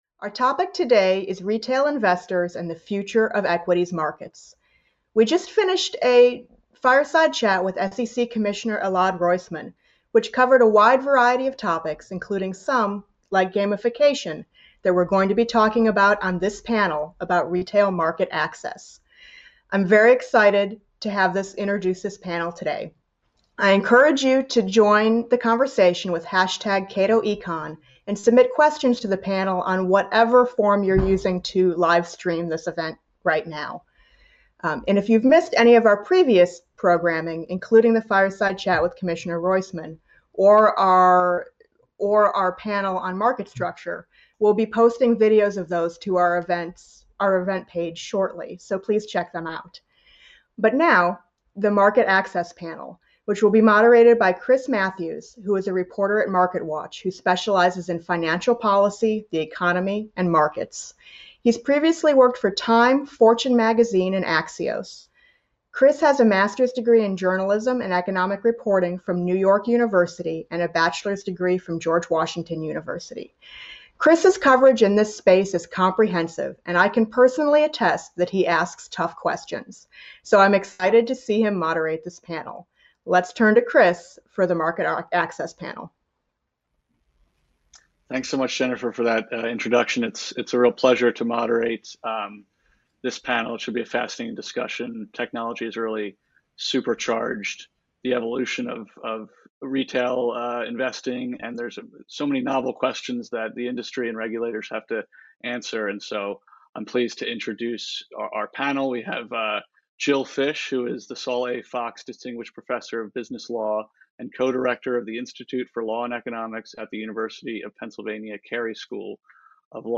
Join us for an outstanding virtual program featuring leading policymakers and experts at Cato’s seventh annual Summit on Financial Regulation.